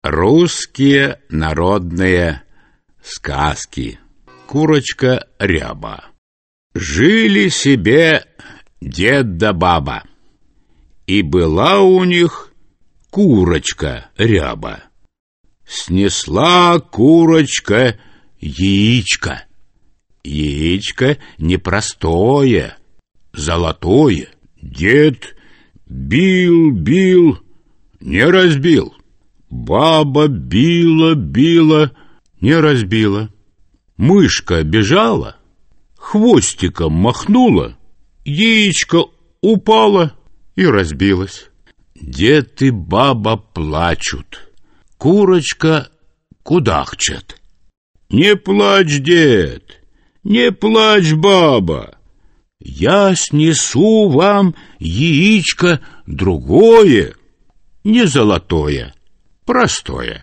Аудиокнига Мои первые русские сказки